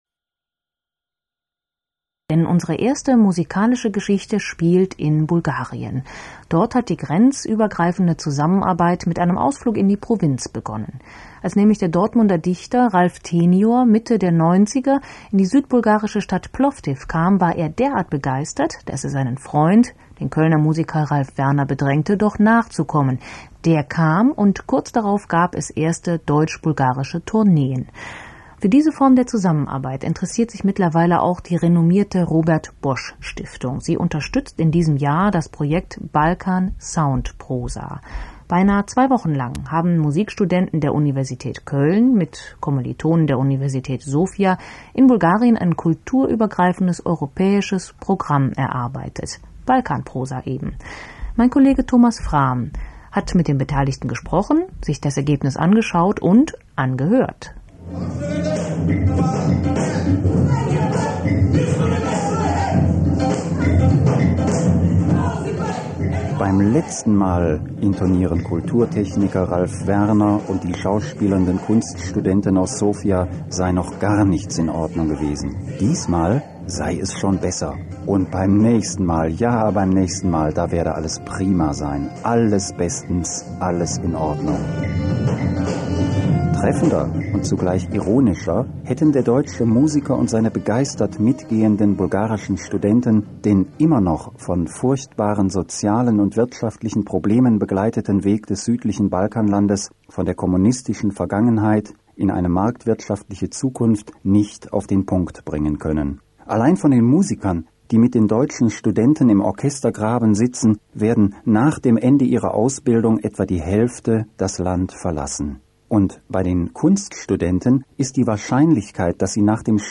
Radio-Feature